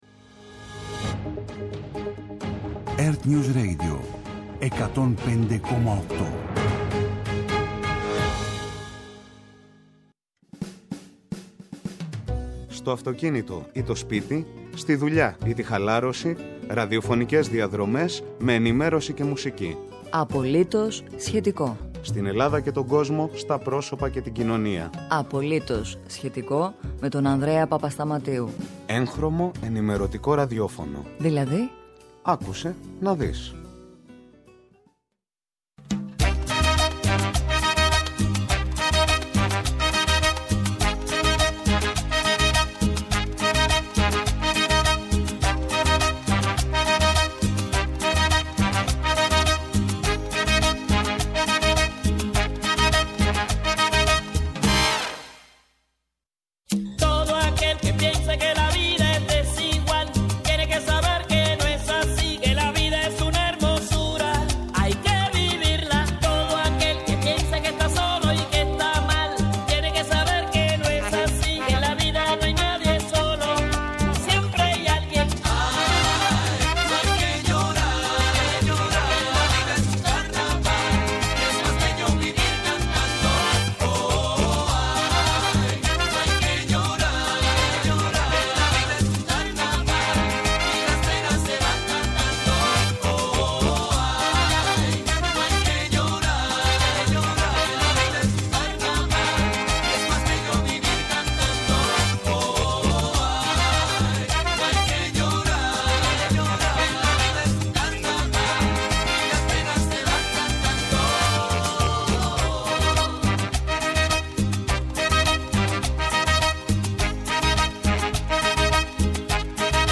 Απόψε η εκπομπή εχει προσαρμόσει το περιεχόμενό της λόγω της συζήτησης που διεξάγεται στη Βουλή για την τροπολογία για τον Αγνωστο Στρατιώτη με τη συμμετοχή και των αρχηγών των κομμάτων. Ετσι παρακολουθούμε, σε απευθείας σύνδεση, αποσπάσματα απο τις ομιλίες του προέδρου του ΠΑΣΟΚ Νίκου Ανδρουλάκη και του προέδρου του ΣΥΡΙΖΑ Σωκράτη Φάμελλου.